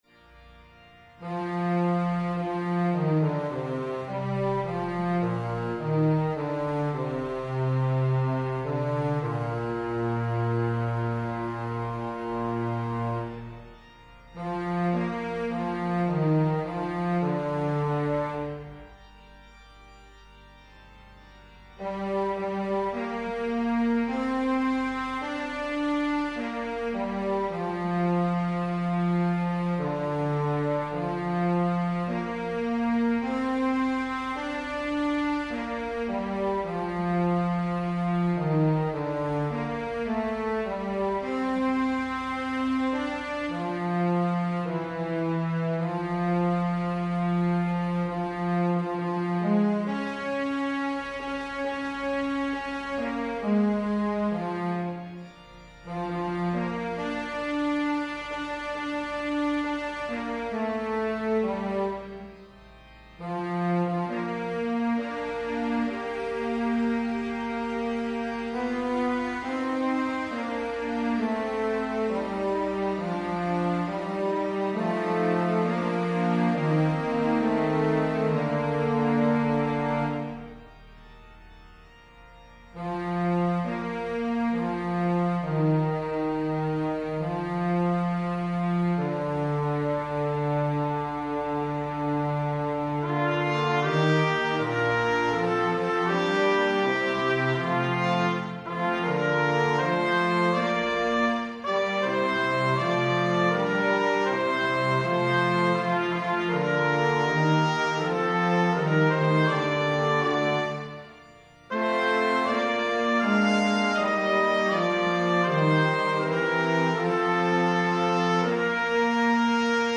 Soprano
Evensong Setting